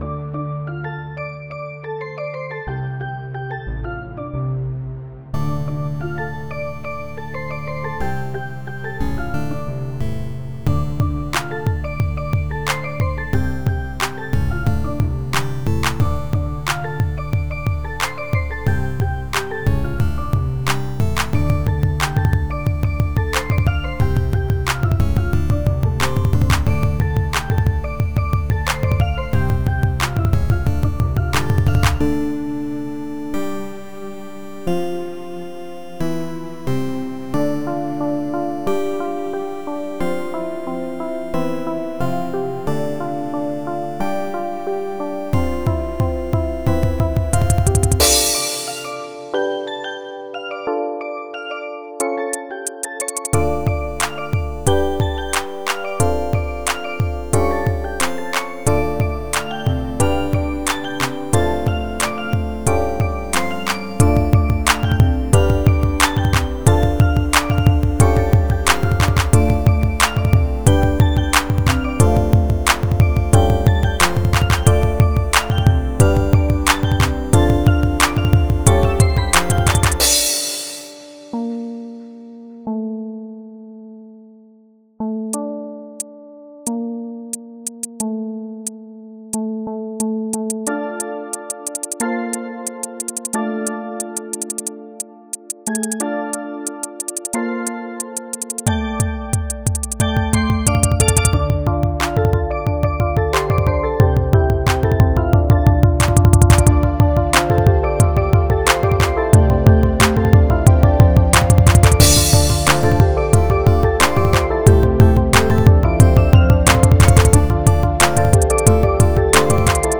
This song includes a cymbal sample